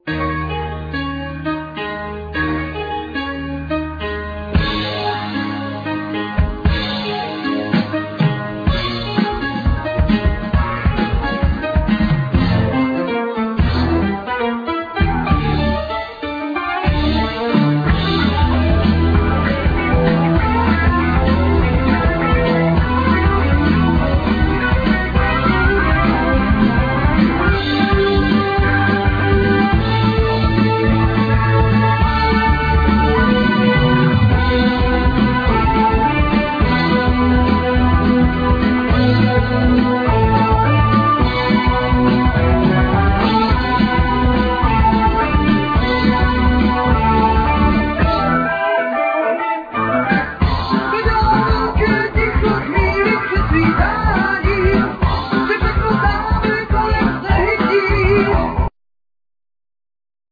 Micromoog, ARP Omni, Clavinet,Fender piano,Piano,Vocal
Ac. guitar,Micromoog, Ckavinet, Cello, Vocal
Micromoog, ARP Omni, Fender piano, Violin, Vocal
El. guitar, Vocal
Drums, Percussion, Micromoog, Vocal